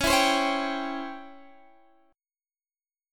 C#mM7b5 chord